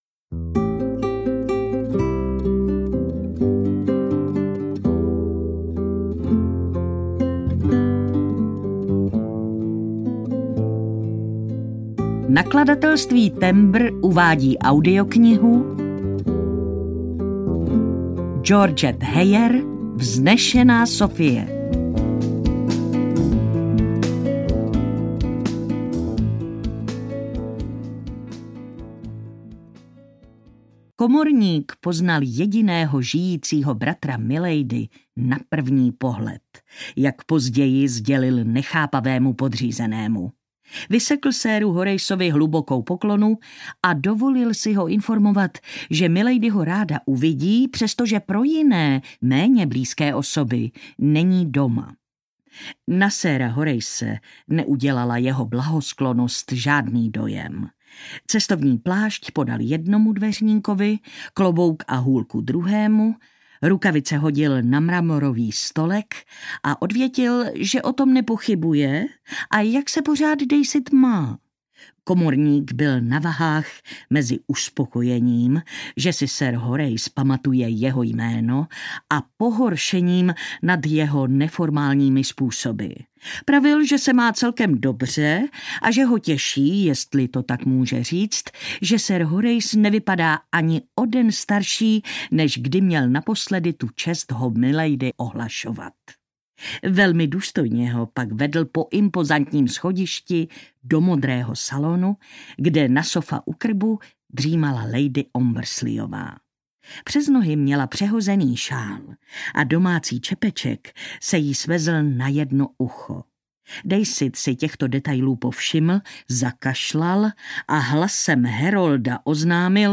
Vznešená Sophie audiokniha
Ukázka z knihy
• InterpretMartina Hudečková